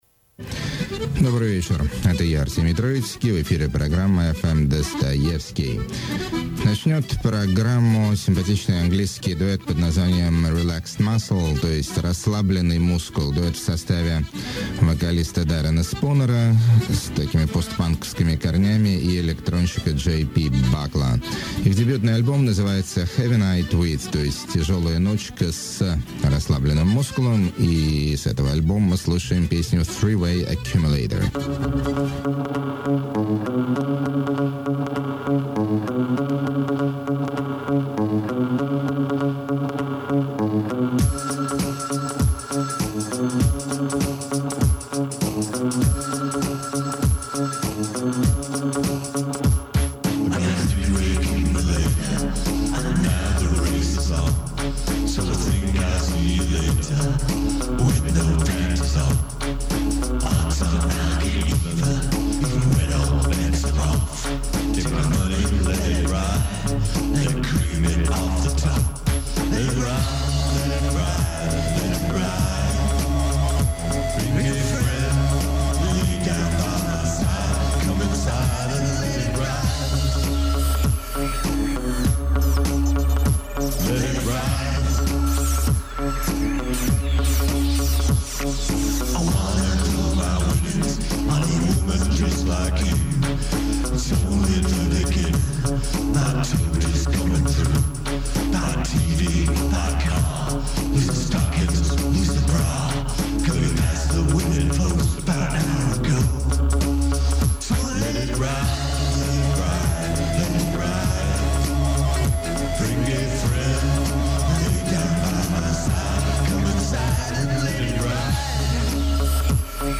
ebm meets trash
cacophony blues
instrumental electropop groove
wild style vocal flamenco
rocky trip-hop
epic drone rock
weird-ish folk rock
dadaistic blip-hop